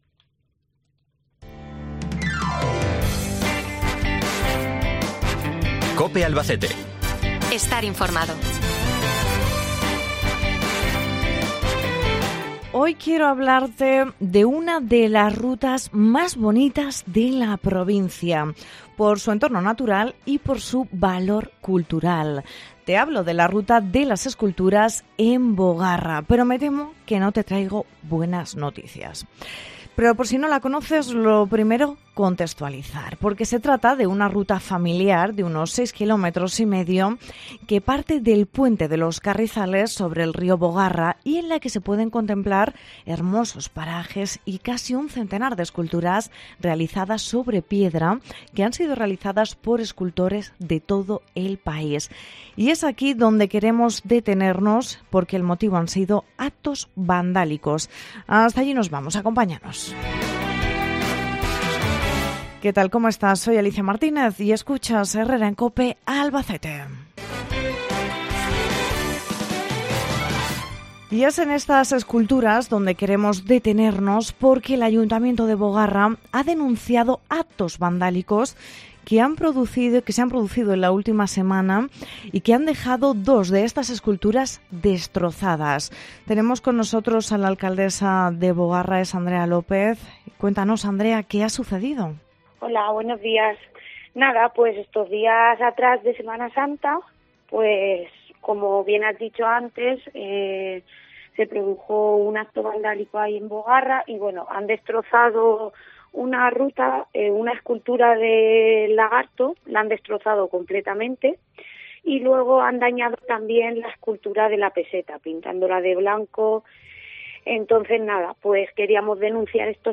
Entrevista Andrea López, alcaldesa de Bogarra